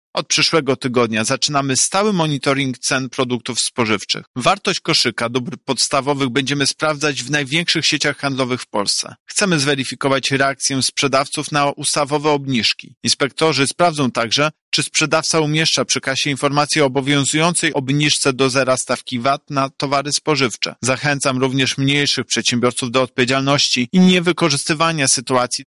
-mówi Prezes UOKiK Tomasz Chróstny